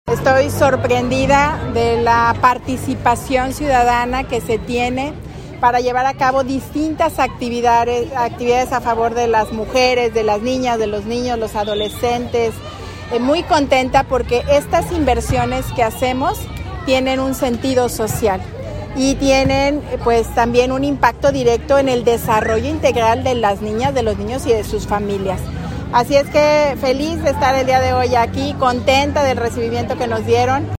AudioBoletines
Lorena Alfaro, presidenta municipal